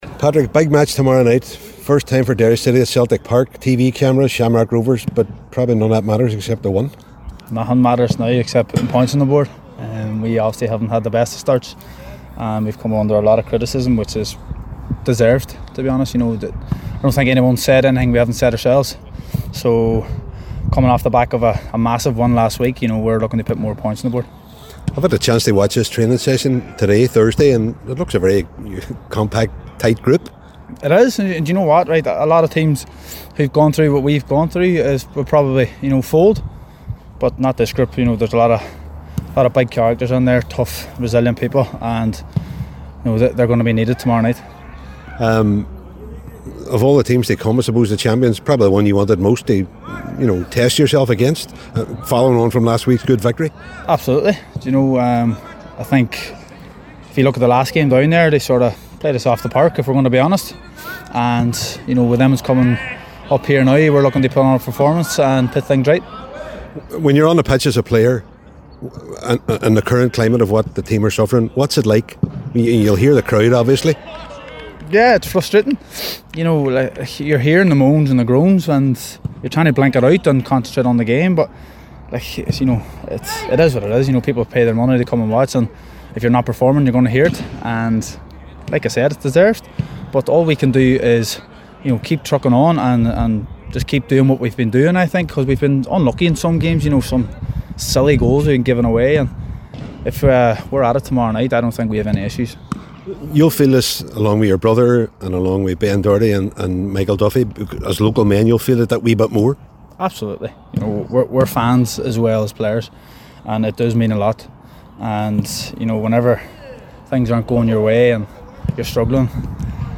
a press event in the lead up to the game